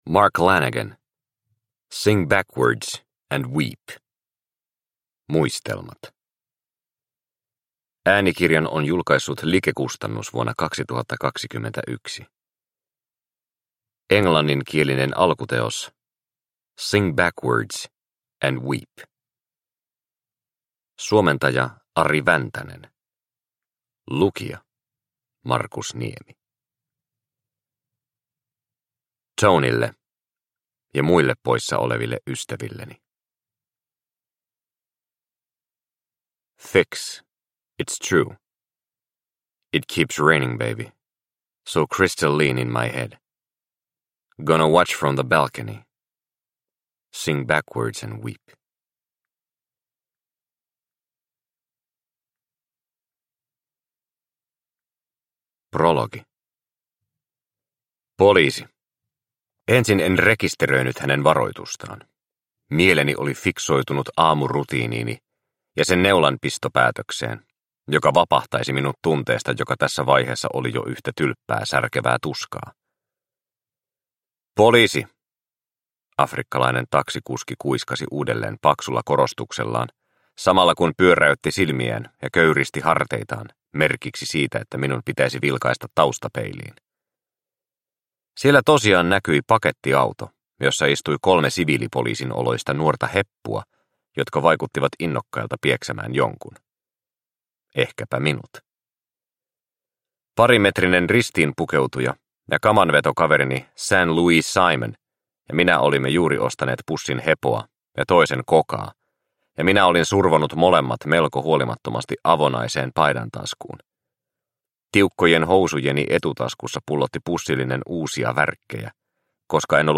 Sing Backwards and Weep – Ljudbok – Laddas ner